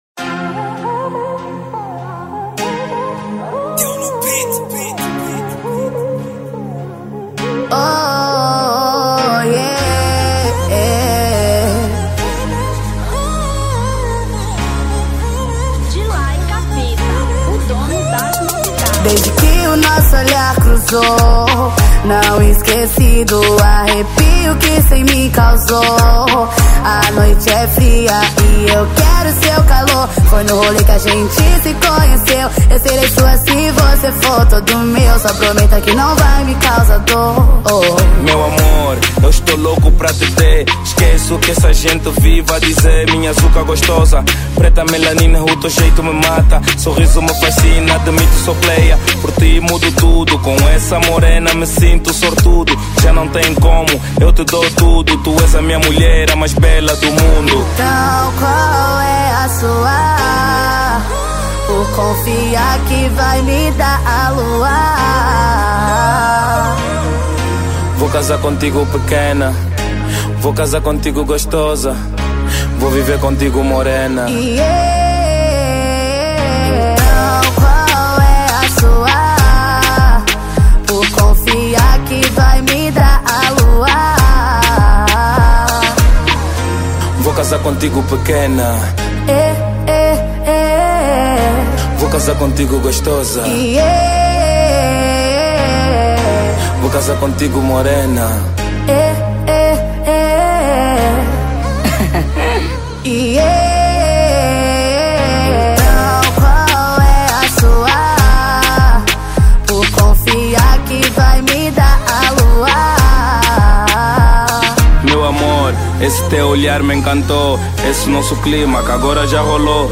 Afro Pop 2020